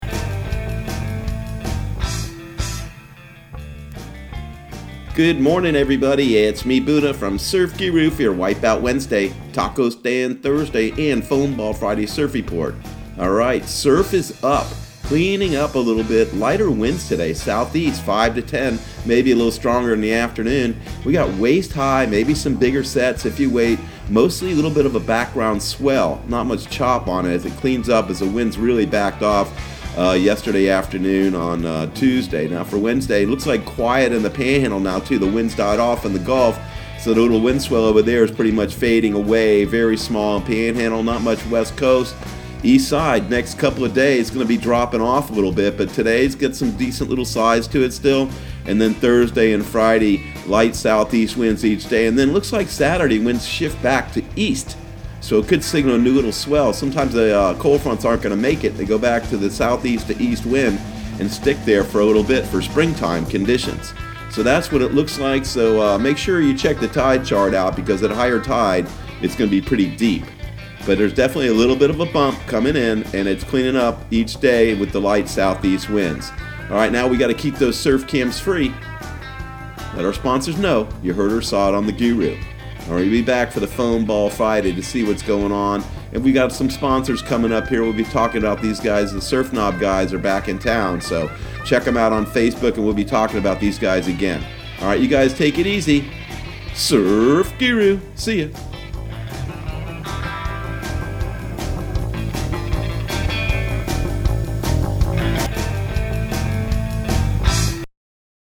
Surf Guru Surf Report and Forecast 03/11/2020 Audio surf report and surf forecast on March 11 for Central Florida and the Southeast.